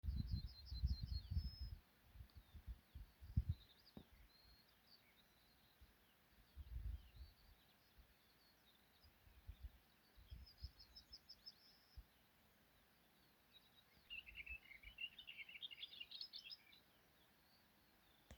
Booted Warbler, Iduna caligata
Administratīvā teritorijaKrustpils novads
StatusSinging male in breeding season